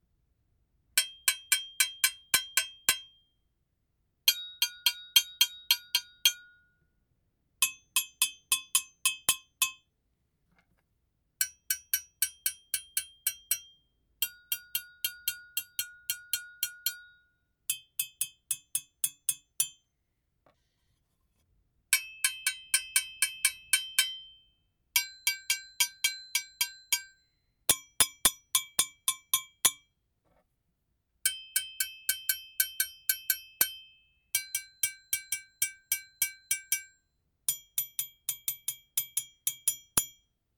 ギニア製 ドゥンドゥンベル セット
リング/スティック~裏リング/スティック